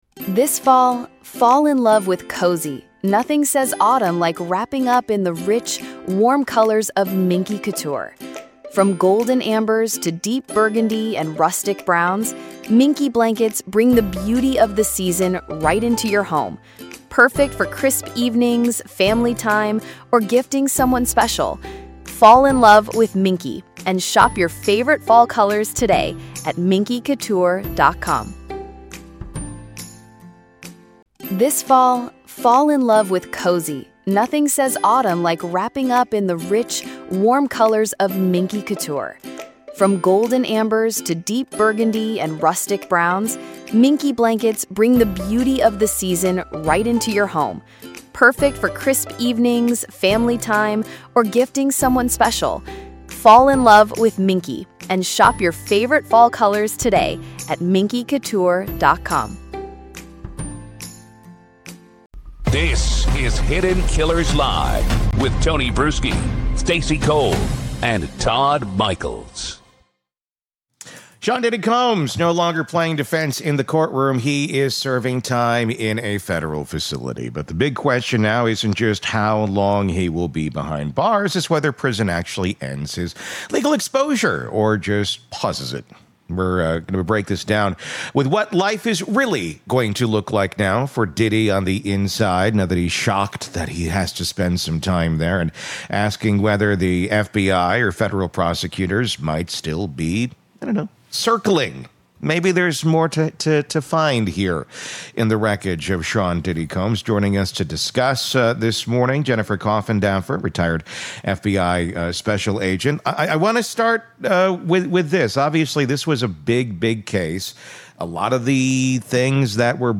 This interview lays bare the next chapter—and how the federal government may still be flipping pages.